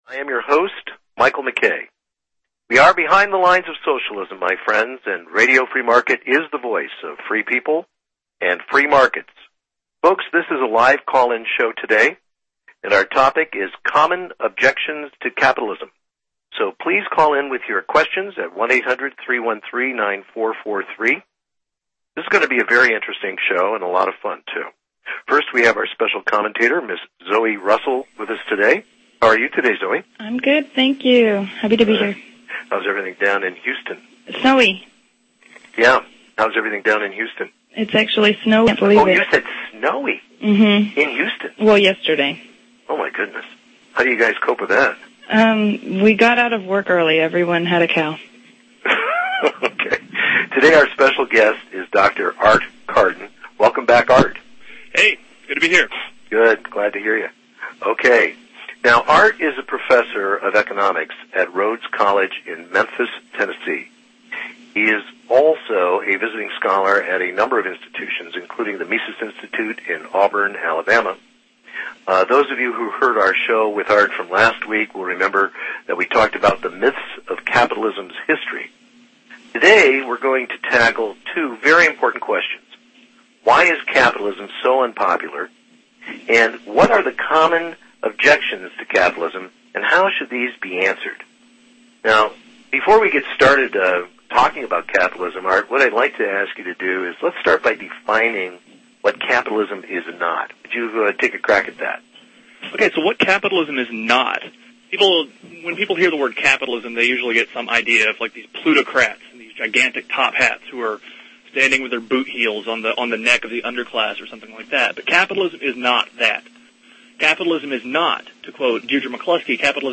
This was such a lively and informative show we were only able to get through about half of the questions we wanted to discuss.